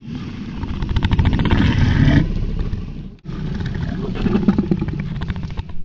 rumble2.wav